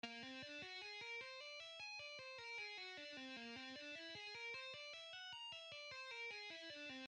Lesson 3: Tapping an Exotic Lick
40% Speed:
Exercise-3-slow-An-exotic-licks-1.mp3